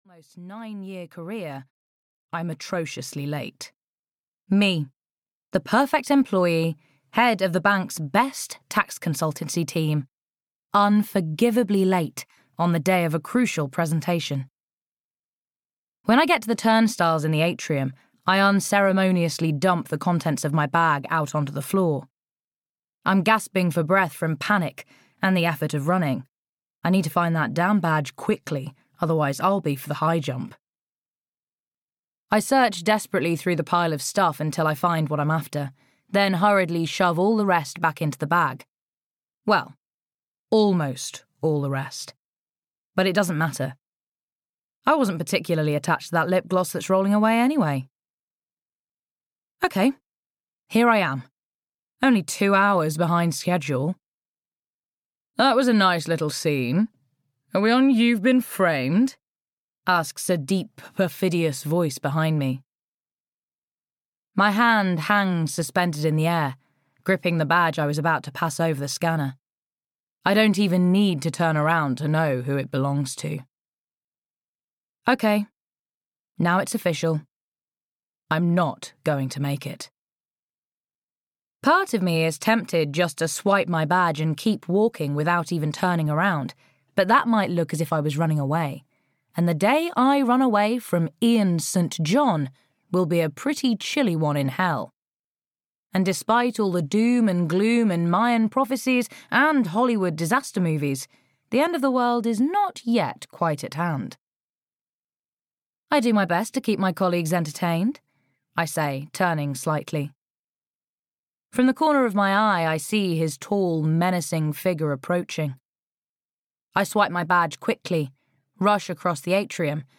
Audio knihaLove to Hate You (EN)
Ukázka z knihy